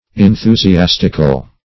\En*thu`si*as"tic*al\, a. [Gr. ? .]
enthusiastical.mp3